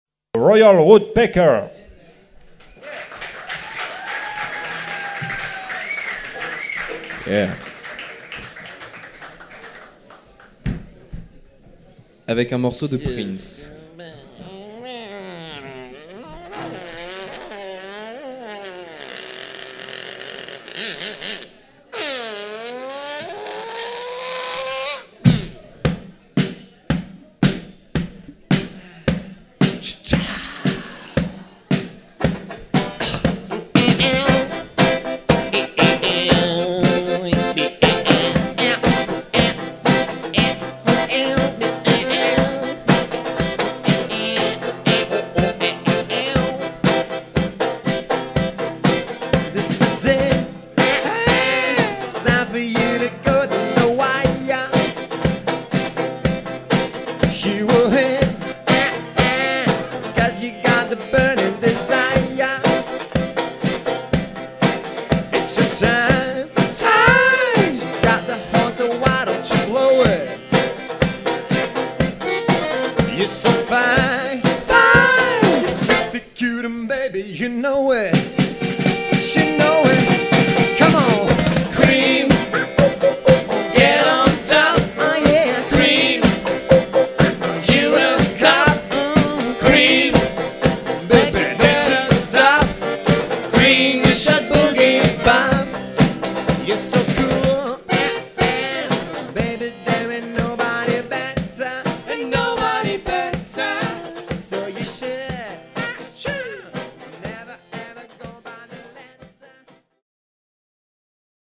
Live (07/05/05), Guinguette chez Alriq